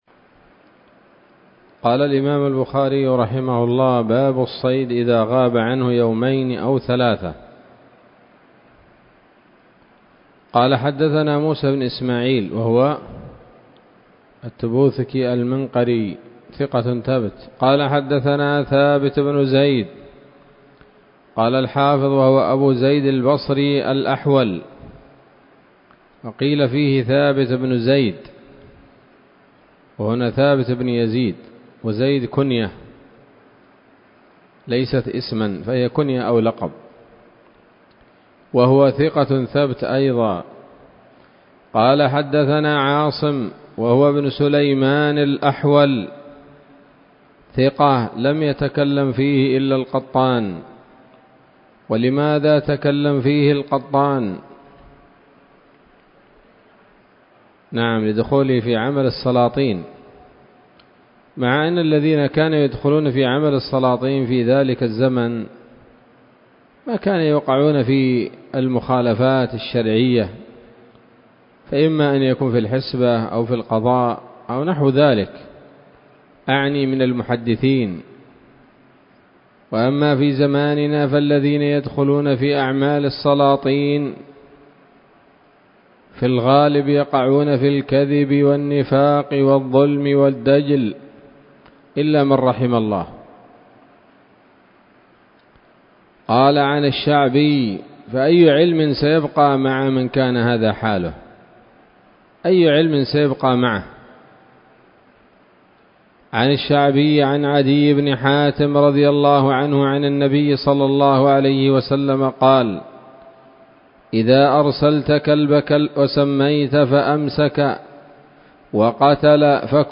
الدرس التاسع من كتاب الذبائح والصيد من صحيح الإمام البخاري